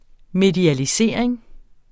Udtale [ medialiˈseˀɐ̯eŋ ]